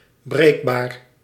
Ääntäminen
IPA: /fʁa.ʒil/